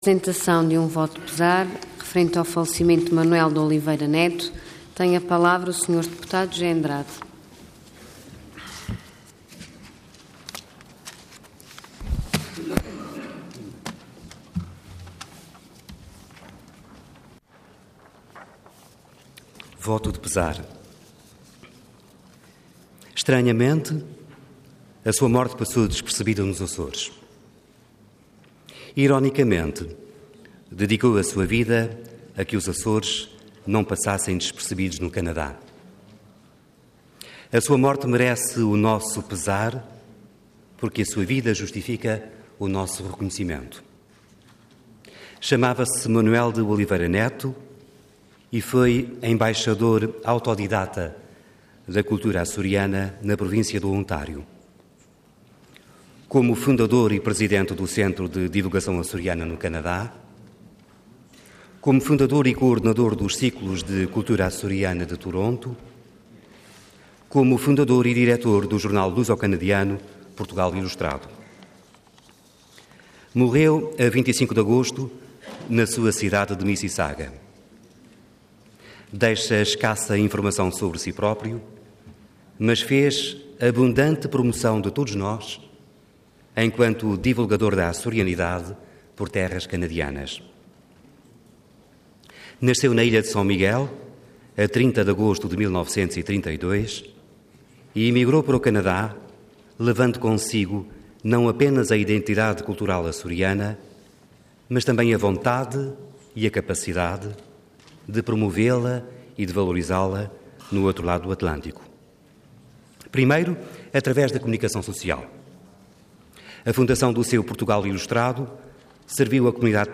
Website da Assembleia Legislativa da Região Autónoma dos Açores
Intervenção Voto de Pesar Orador José Andrade Cargo Deputado Entidade PSD